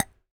metal_tiny_hit_impact_01.wav